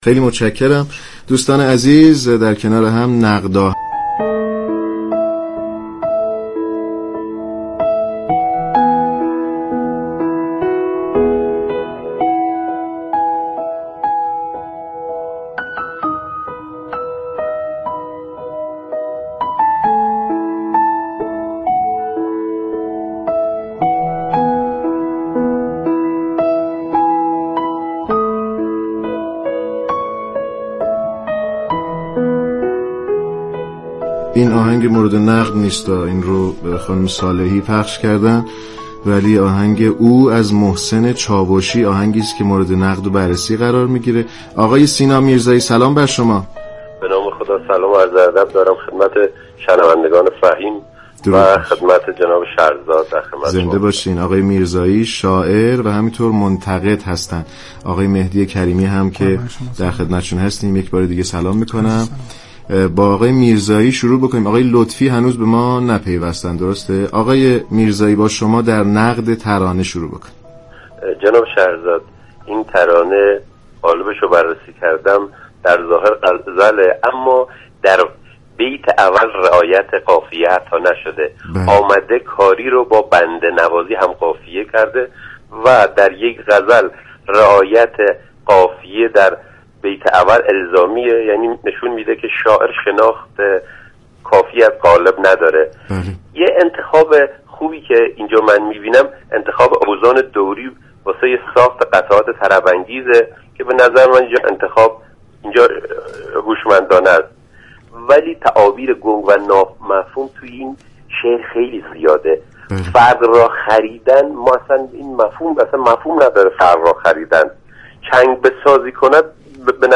نقد آهنگ
گفت و گوی رادیویی
خواننده، آهنگساز و معلم آواز به روی خط برنامه آمد